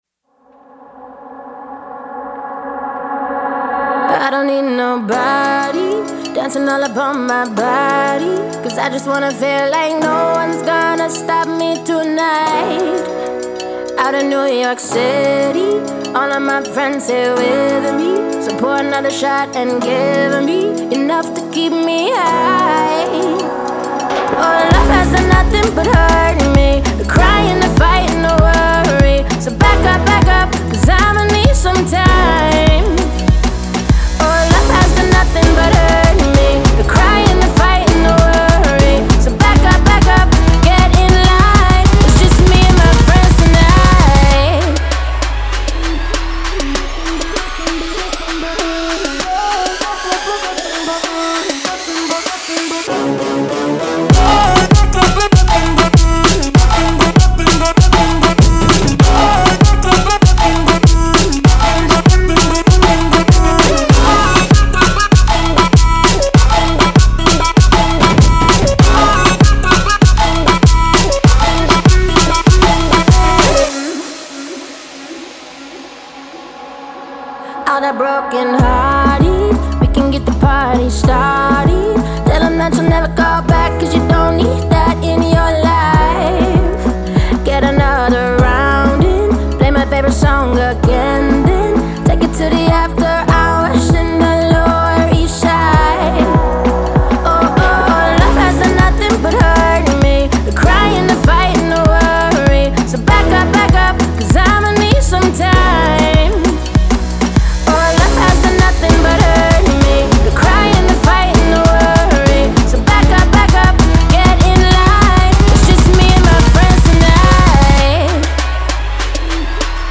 зажигательная поп-песня